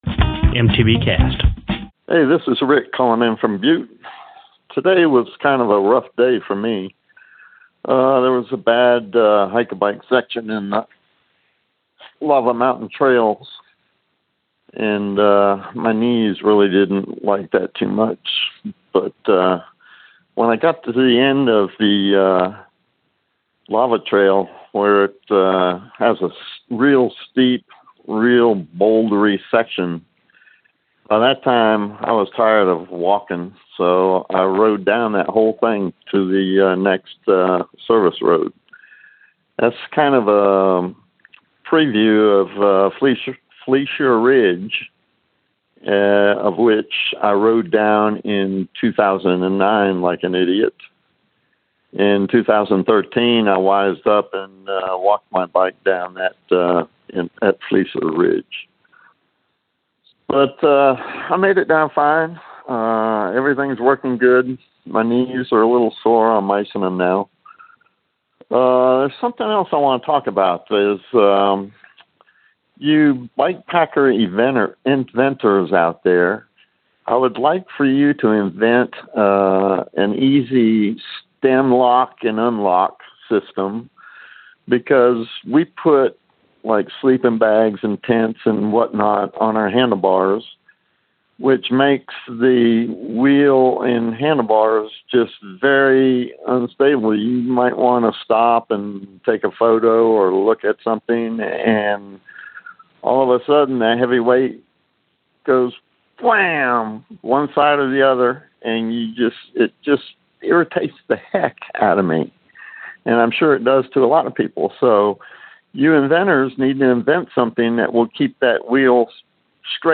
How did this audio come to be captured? called in from Butte!